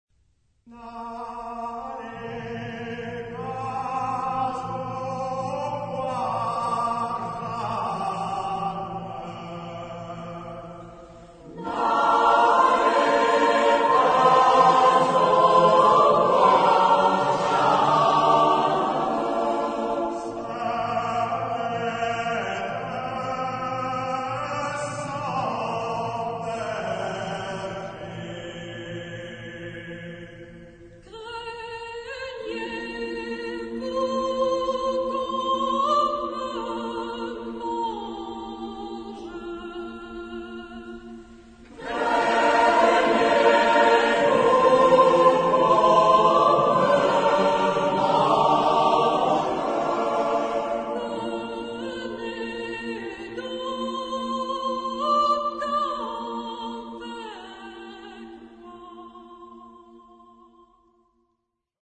Genre-Style-Forme : Populaire ; Traditionnel ; Profane
Type de choeur : SATB  (4 voix mixtes )
Solistes : Soprano (1) OU Tenor (1)  (2 soliste(s))
Tonalité : si bémol majeur